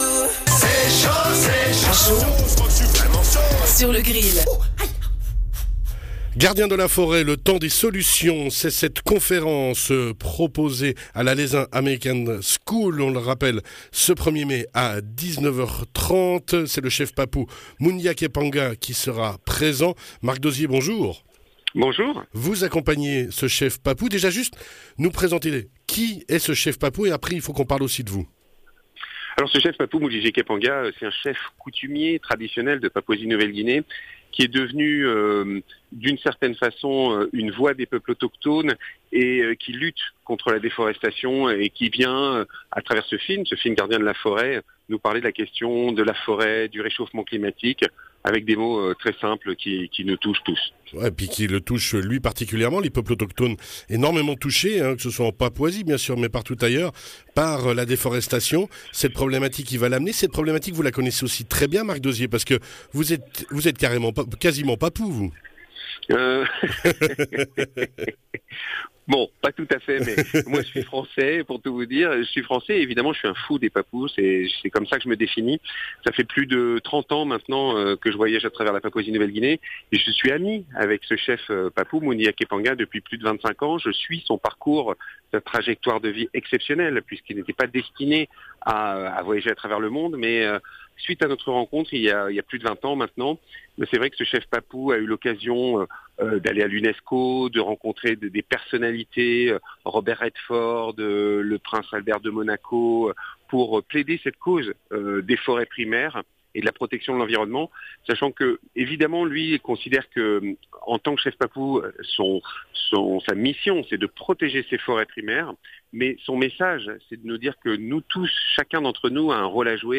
Un Chef Papou en conférence à Leysin - 2ème partie